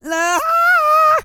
E-CROON 3031.wav